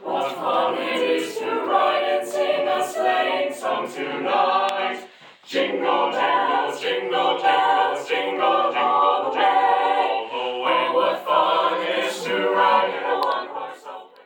The Emporia High School Chorale serenades visitors to Red Rocks State Historic Site during Christmas at the White House.
Performances included a string quartet, Christmas carols from the Emporia High School Chorale, and Emporia Children’s Choir selections.
4374-jingle-bells.wav